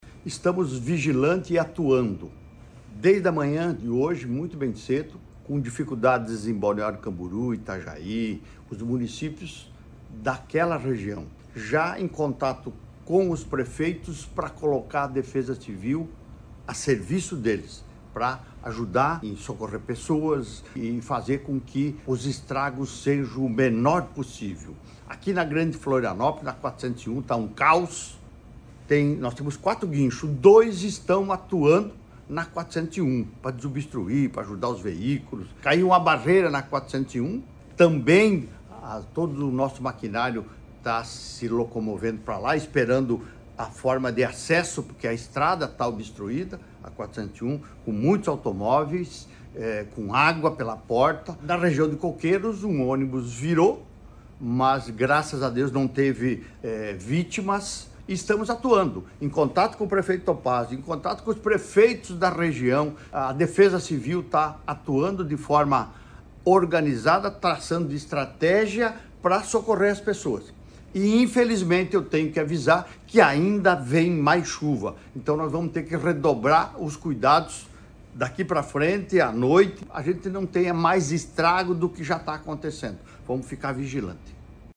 Segundo o governador Jorginho Mello as equipes do Governo seguem empenhadas com força máxima para atender a população:
SECOM-Sonora-governador-Chuvas-em-SC-1.mp3